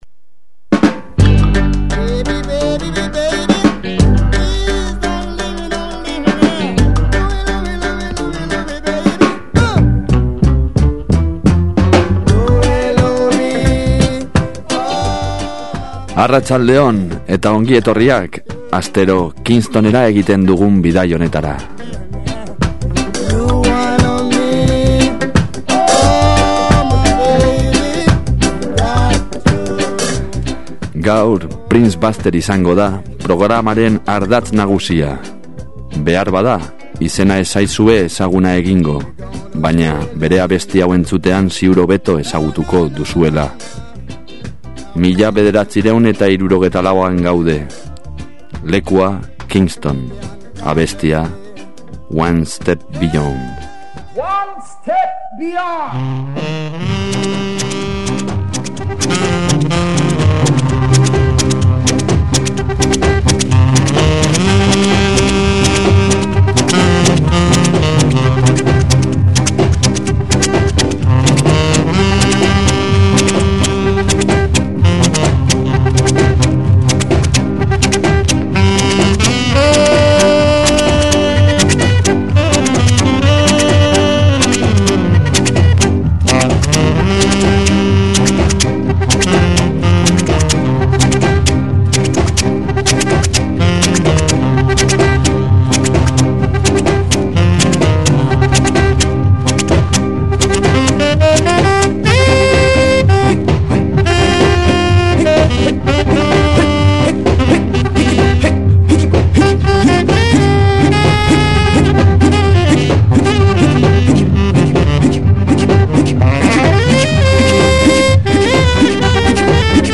Kingston Hiria irratsaioak aro berri bat hasten du.
PRINCE BUSTER Skaren printzea eta “asmatzailea” izan dugu mintzagai. Horretaz aparte, JOSU ZABALArekin hitz egin dugu Hertzainak taldearen “Egunero” abestia dela eta, hamaika kontu esan dizkigularik abesti horri buruz, diskoaren grabaketari buruz eta baita “sasoi latz” haiei buruz.